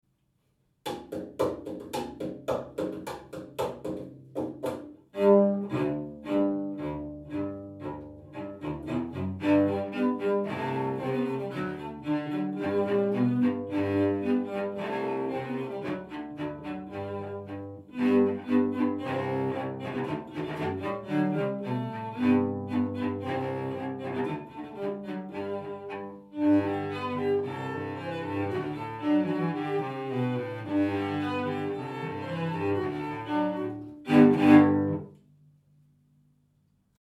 Instrument: Cello (Violoncello)
Es wird sitzend gespielt und mit einem Bogen gestrichen oder den Fingern gezupft. Sein Klang ist viel tiefer und wärmer als jener der Violine oder Viola.